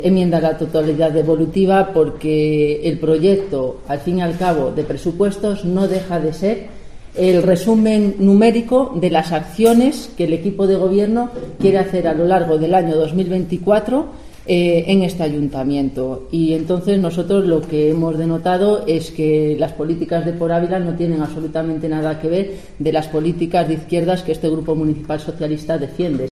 Esto contestaba Eva Arias, portavoz del PSOE, a las preguntas de la prensa... (ESCUCHAR AUDIO)